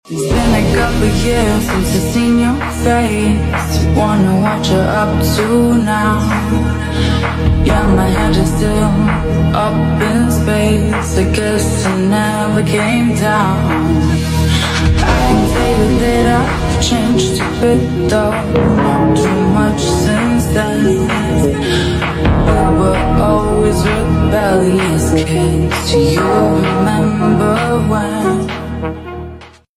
it's quite noisy because i'm doing this in front of the street